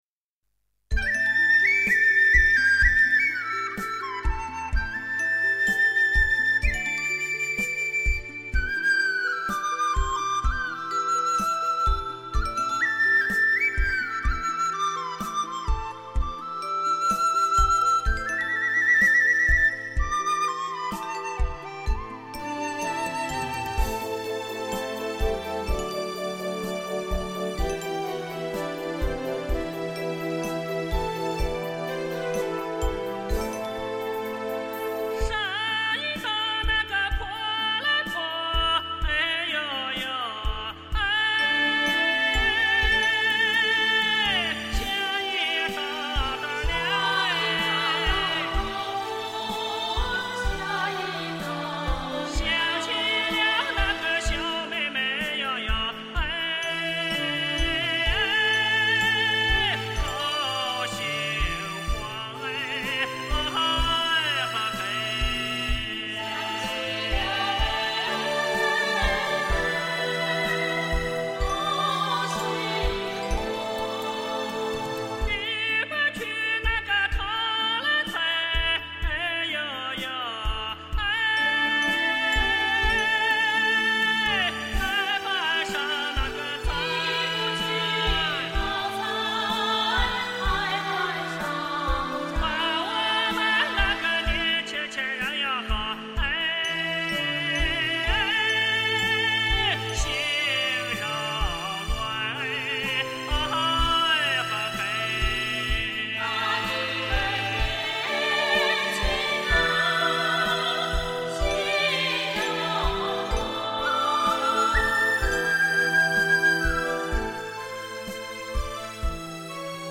比较地道的陕北民歌专辑
前言： 我是陕西人，很喜欢陕北民歌，朴实直爽，也喜欢站在大路上吼上几句，因为它绝没有半点矫饰，感情永远是那样真诚。
希望大家能够喜欢，这个专辑是我从同事那里找到的陕西省录制的双CD中精选出来的，基本上保留了陕北民歌的风韵。